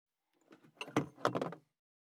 221,食器,グラス,コップ,工具,小物,雑貨,コトン,トン,ゴト,ポン,ガシャン,ドスン,ストン,カチ,タン,バタン,スッ,サッ,コン,
コップ効果音物を置く